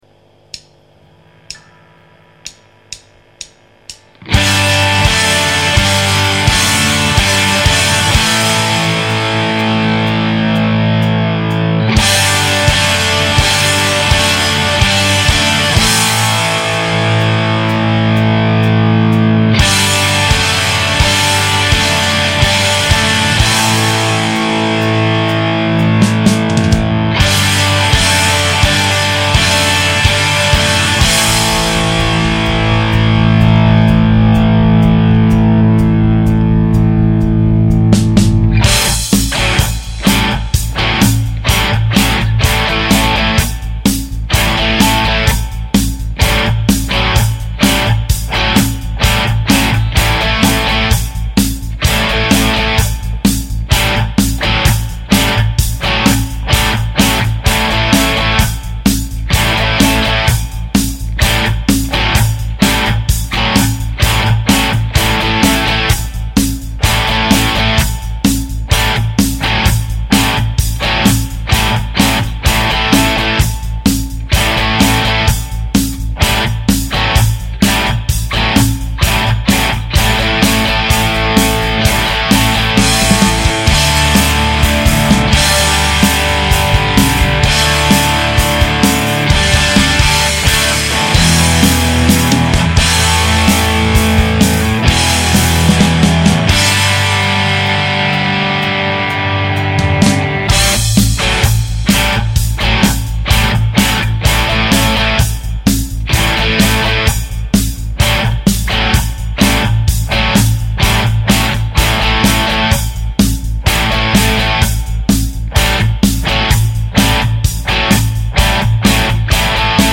Faites écouter vos guitares ici ! [version électrique] - Guitare électrique - Page 45
aller hop Squier(montée en 498T) +Jackhammer+Valvestate 40 http